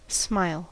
Ääntäminen
Ääntäminen US
IPA : /smaɪl/